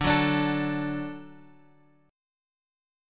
D chord {x 5 4 x 3 5} chord
D-Major-D-x,5,4,x,3,5.m4a